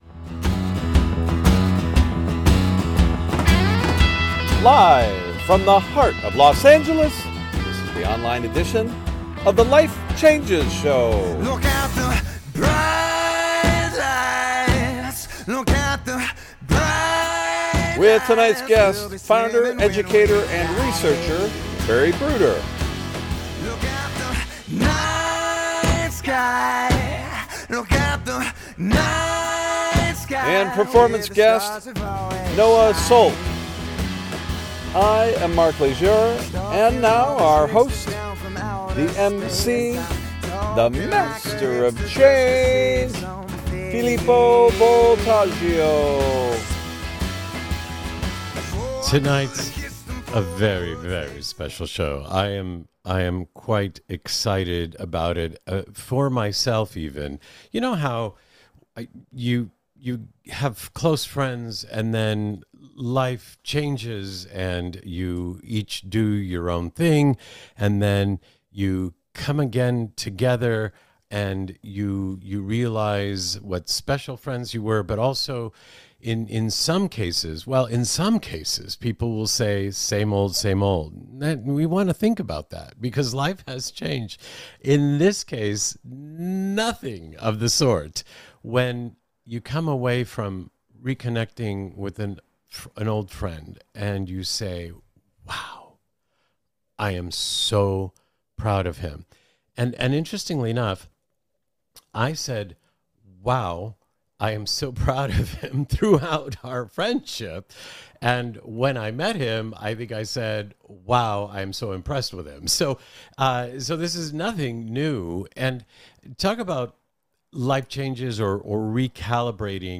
Talk Show Episode
Interview Guest